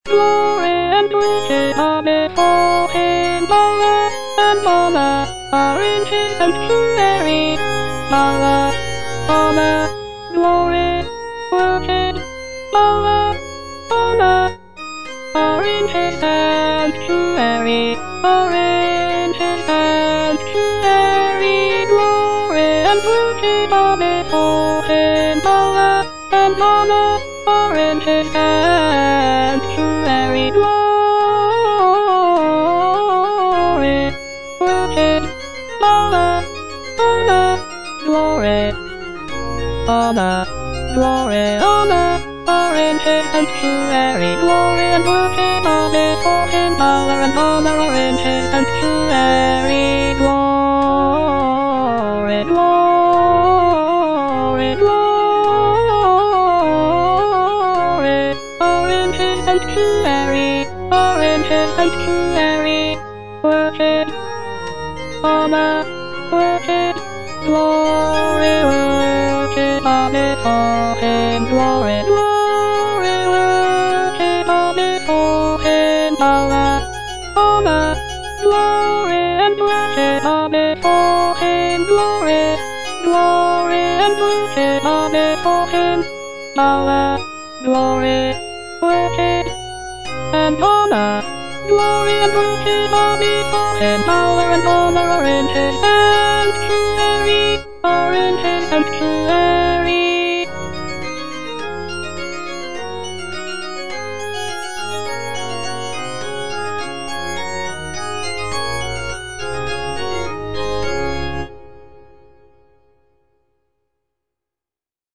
G.F. HÄNDEL - O COME, LET US SING UNTO THE LORD - CHANDOS ANTHEM NO.8 HWV253 (A = 415 Hz) Glory and worship are before him - Alto (Voice with metronome) Ads stop: auto-stop
The use of a lower tuning of A=415 Hz gives the music a warmer and more resonant sound compared to the standard tuning of A=440 Hz.